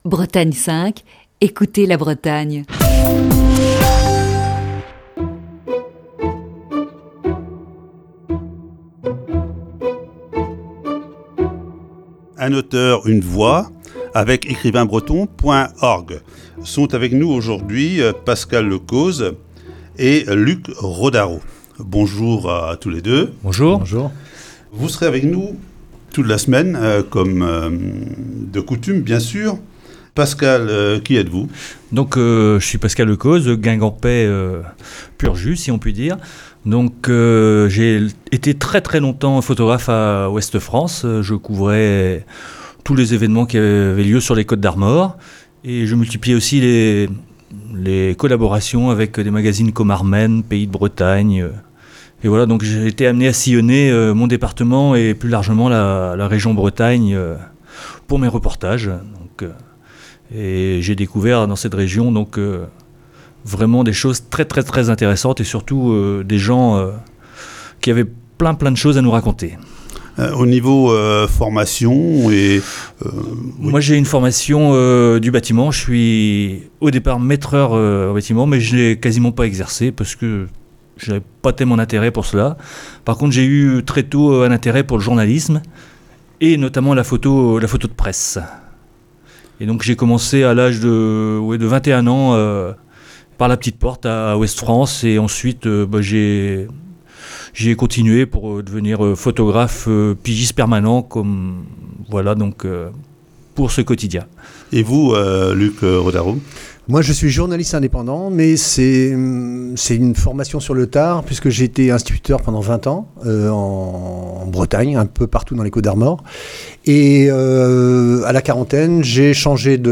Voici ce lundi la première partie de cette série d'entretiens.